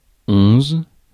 Ääntäminen
Ääntäminen France: IPA: [ɔ̃z] Haettu sana löytyi näillä lähdekielillä: ranska Käännöksiä ei löytynyt valitulle kohdekielelle.